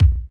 drum32.ogg